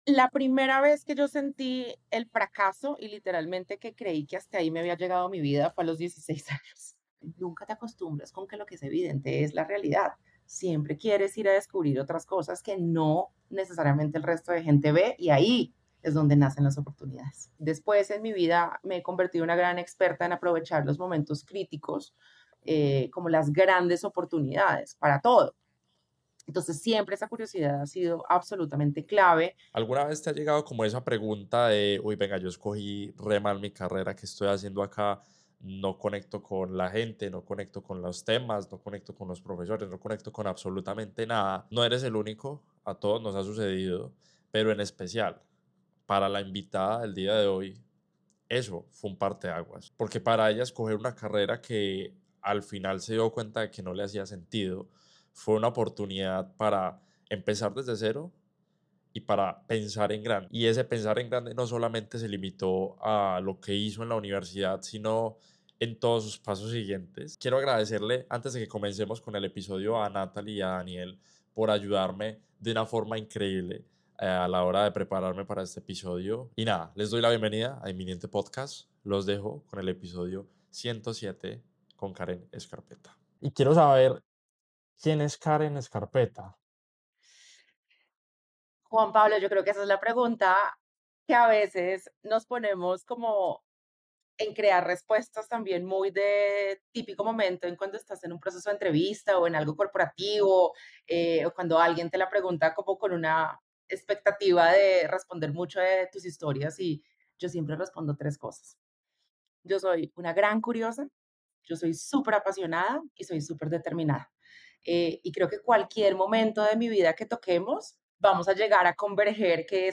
Aprendemos de la experiencia ajena a través de conversaciones sinceras.